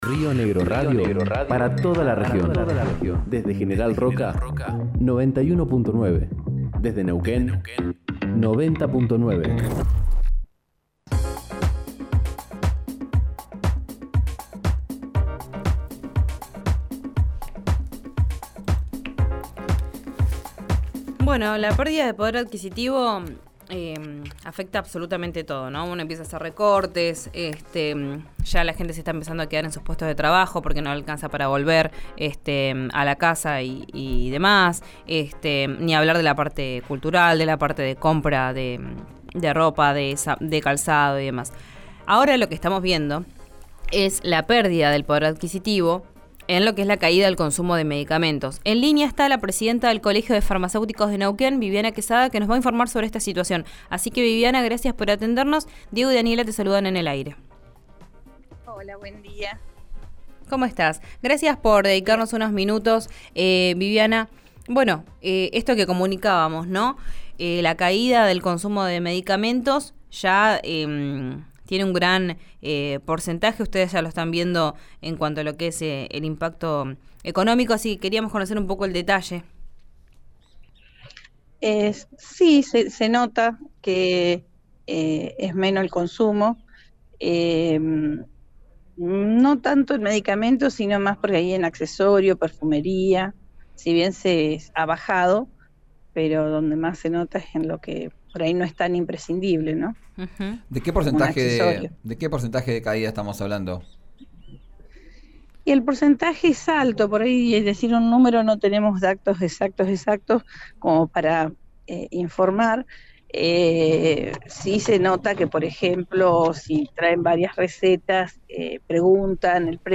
En conversación con RIO NEGRO RADIO